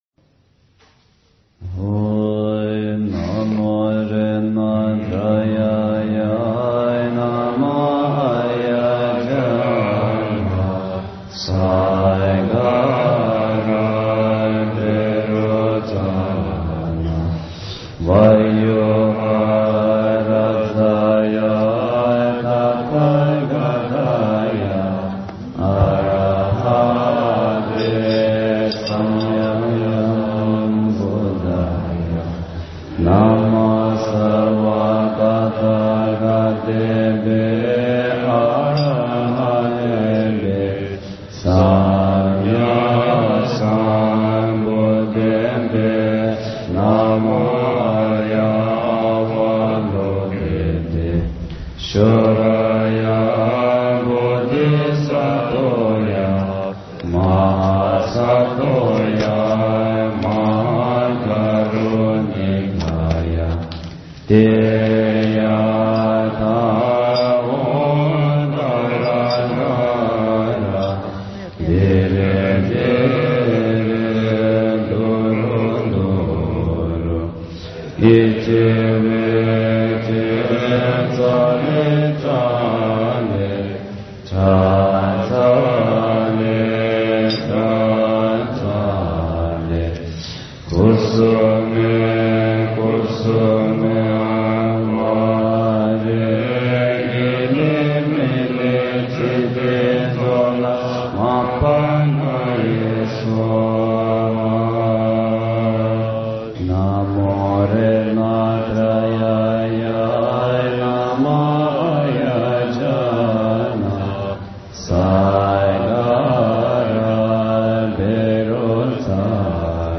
大悲咒(领颂版) 诵经 大悲咒(领颂版)--大宝法王 点我： 标签: 佛音 诵经 佛教音乐 返回列表 上一篇： 大悲咒 下一篇： 般若波罗蜜多心经 相关文章 朝山礼拜--圆光佛学院众法师 朝山礼拜--圆光佛学院众法师...